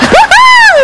Atomix huhú effekt
huhu.wav